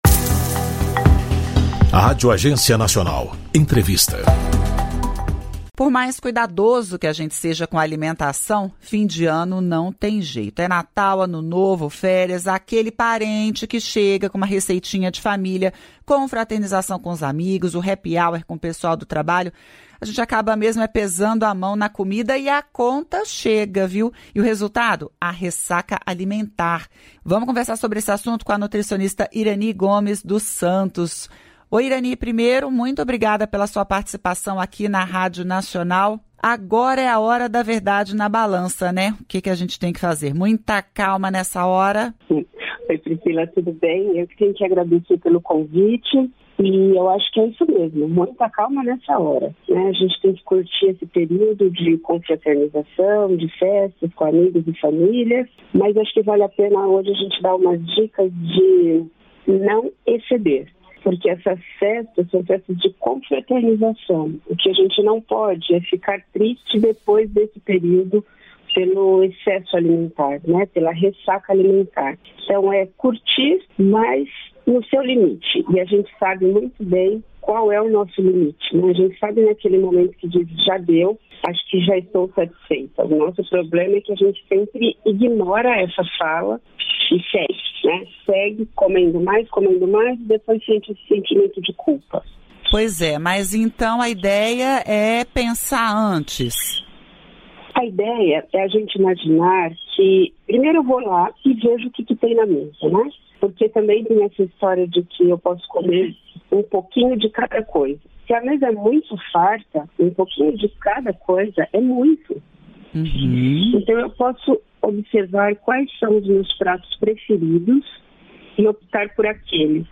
Entrevista: nutricionista dá dicas para evitar ressaca alimentar